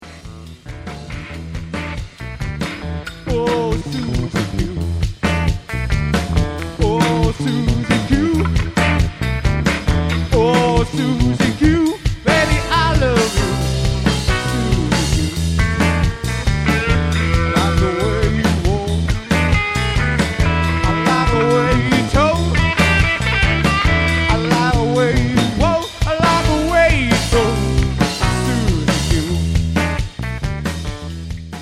(några sikund fråån Mustakari i Juli 1999)